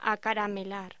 Locución: Acaramelar